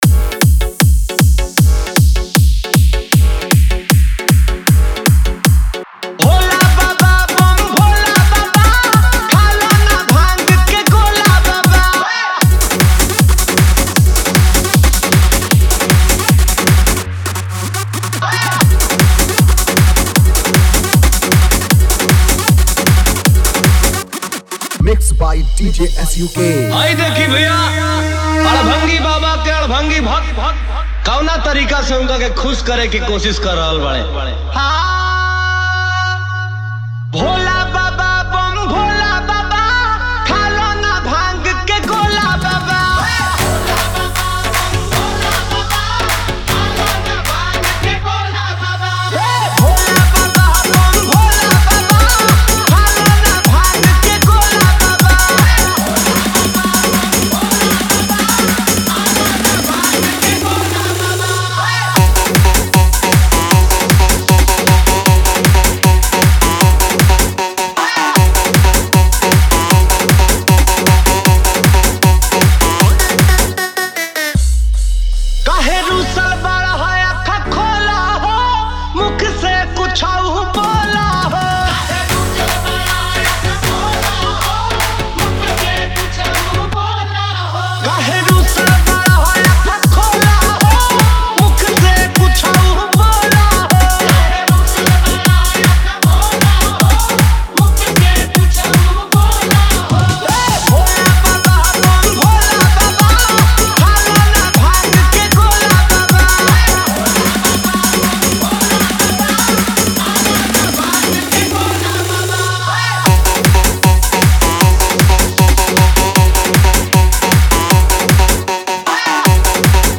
DJ Remix
Bhakti DJ Remix Songs